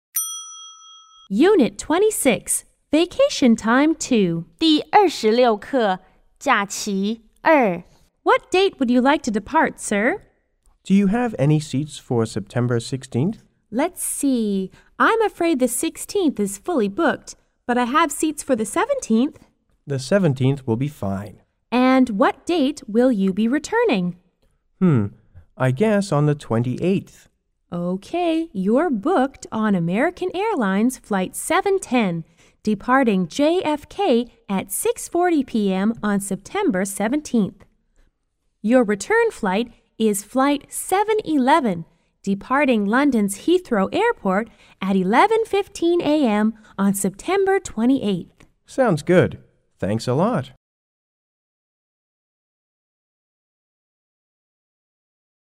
T= Travel Agent C= Caller